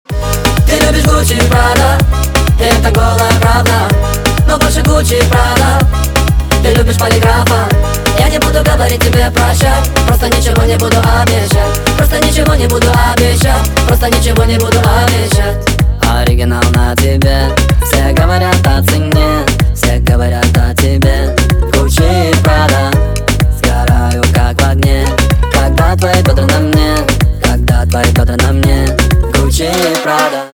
• Качество: 320, Stereo
поп
ритмичные
заводные
цикличные